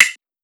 normal-hitnormal-1.wav